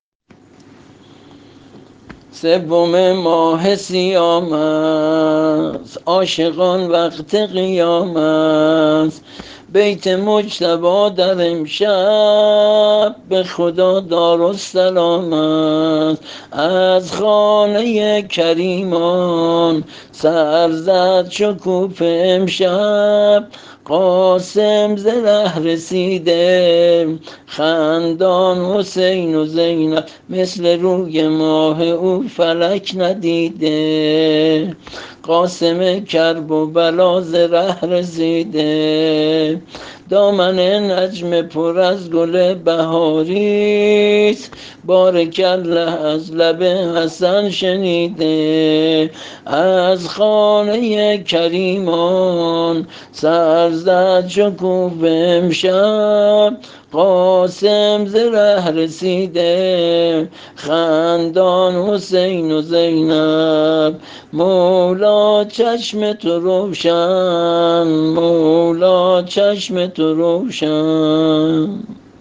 زمزمه‌وشور سینه‌زنی